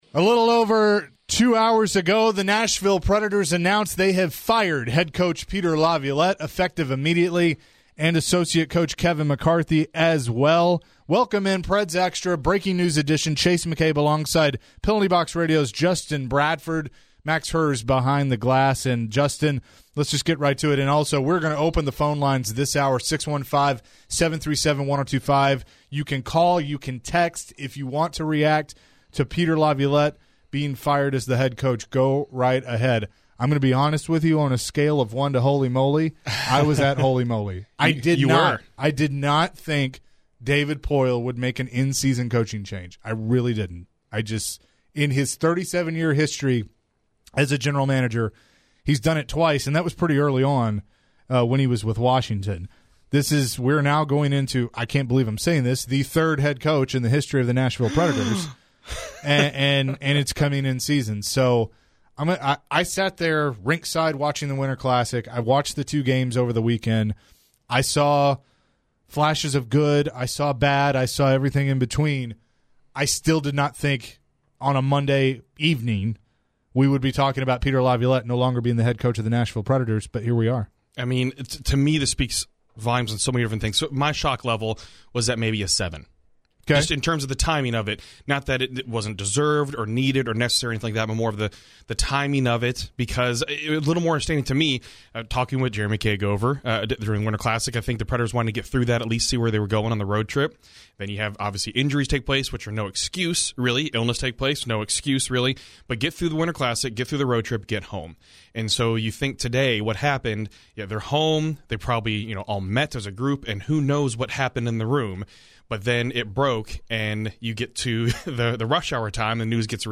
live reaction to the Predators firing Peter Laviolette Monday evening on ESPN 102.5 The Game.